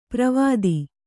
♪ pravādi